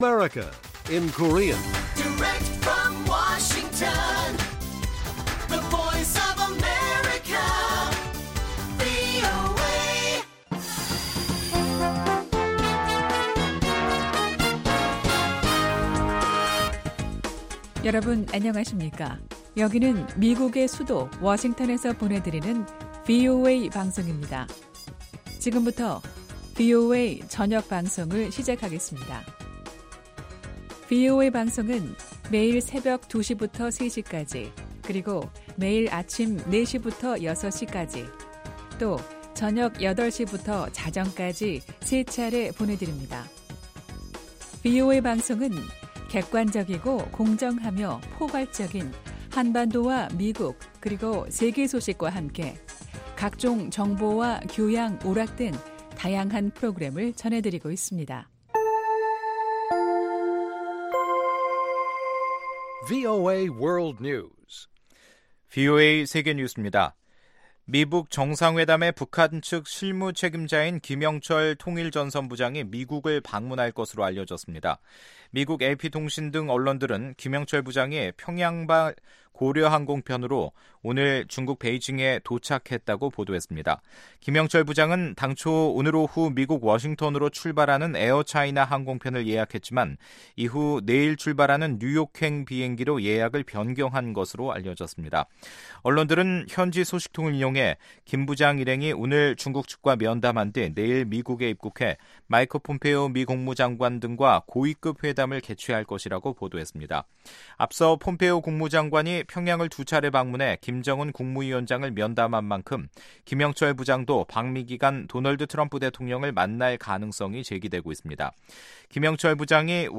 VOA 한국어 간판 뉴스 프로그램 '뉴스 투데이', 2018년 5월 29일 1부 방송입니다. 북한의 김영철 통일전선부장이 중국 베이징을 거쳐 미국을 방문할 것으로 알려졌습니다. 미국과 일본은 북한의 완전하고 영구적인 비핵화라는 공동의 목표를 재확인했다고 백악관이 밝혔습니다.